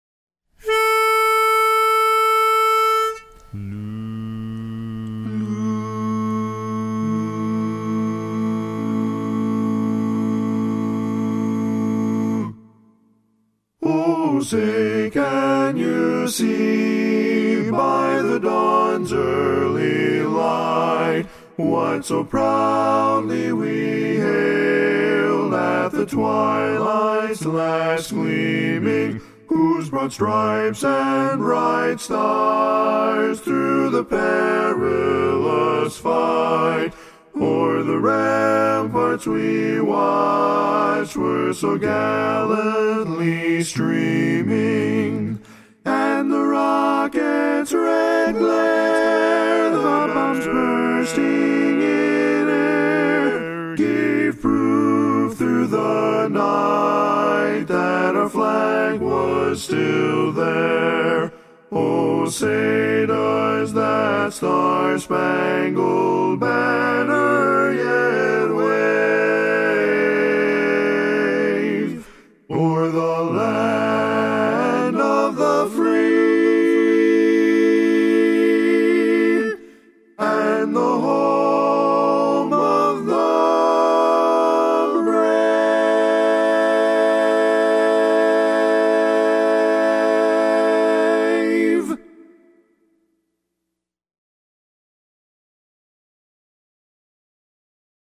Up-tempo
Barbershop
A Major
Lead